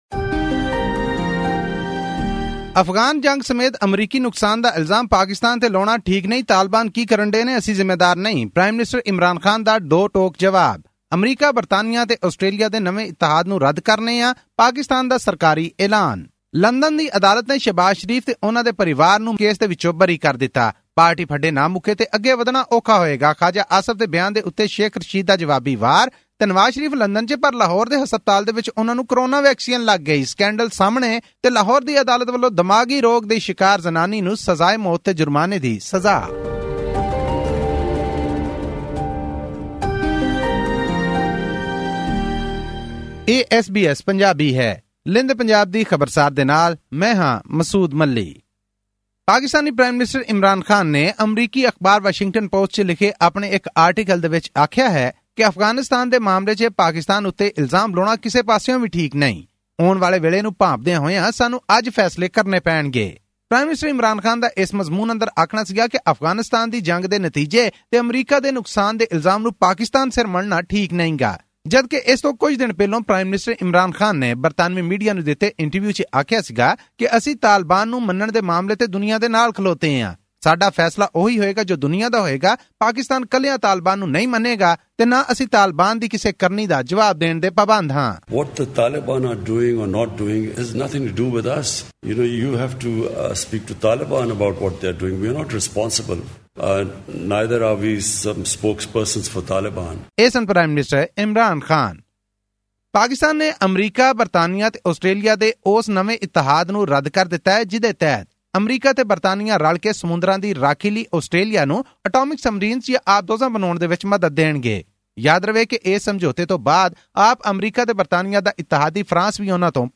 The Punjab Health Department has suspended two officials after a 'fake' coronavirus vaccine certificate was issued under the name of ousted prime minister Nawaz Sharif at a government hospital in Lahore. Tune into this week's news bulletin from Pakistan for all this and more.